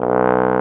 Index of /90_sSampleCDs/Roland L-CD702/VOL-2/BRS_Bs.Trombones/BRS_Bs.Bone Solo